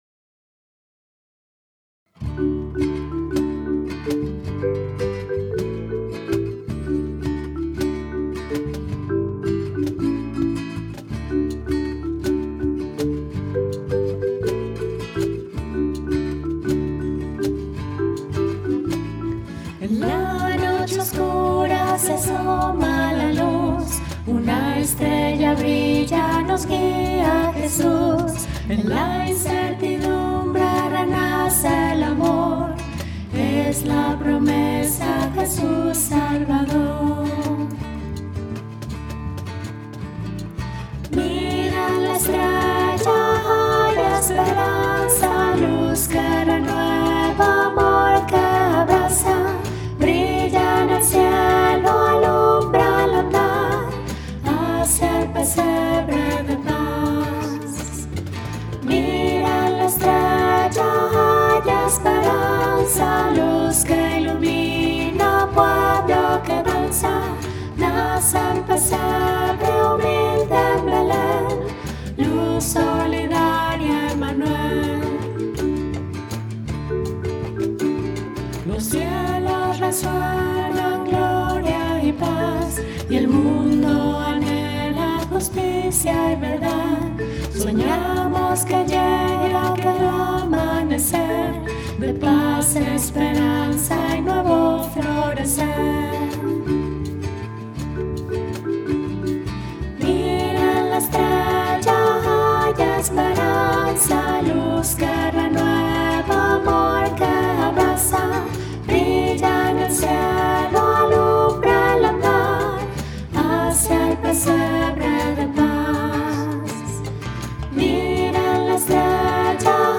Audio Voz: